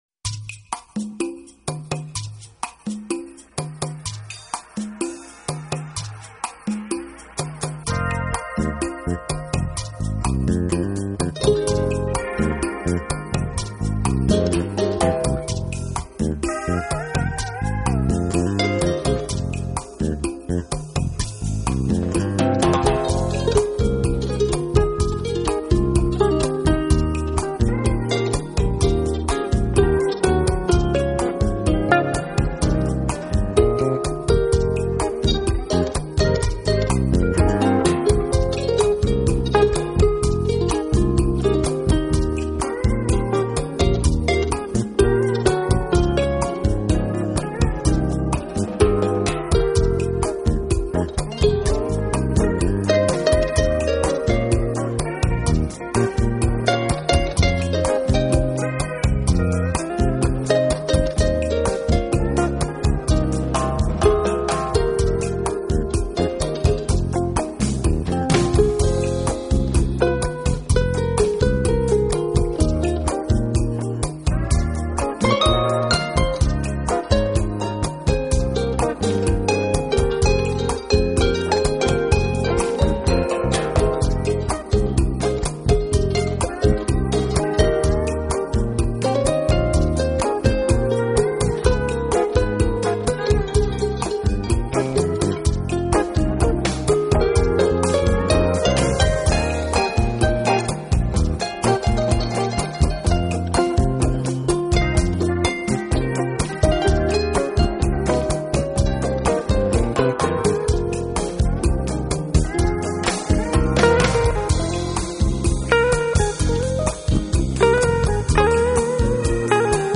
这是一张很清新的音乐，听这让人仿佛置身仙镜。
晶莹透剔的拔弦，挟着天使的温柔把夜中人引向了如仙般的梦境……
本CD收录的作品皆出自模拟录音黄金时代，从